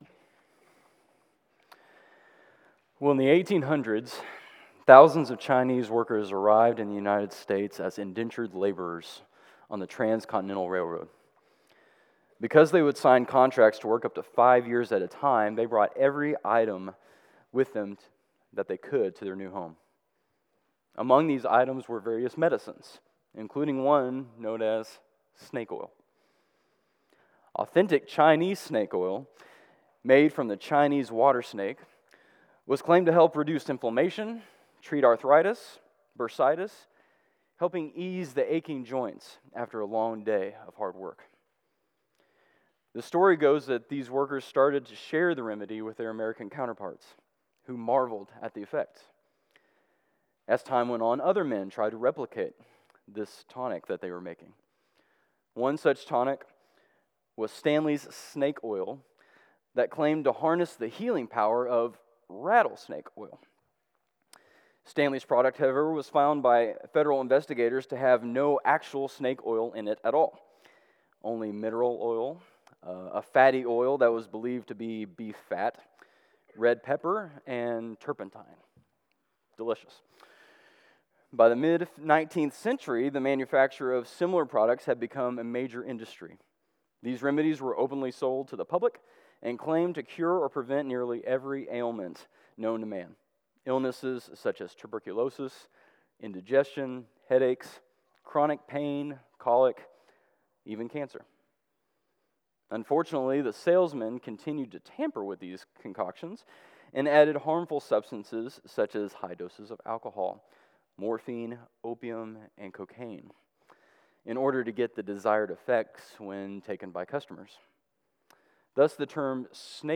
CCBC Sermons 2 Peter 2:17-22 Oct 21 2024 | 00:32:19 1x 00:00 / 00:32:19 Subscribe Share Apple Podcasts Spotify Overcast RSS Feed Share Link Embed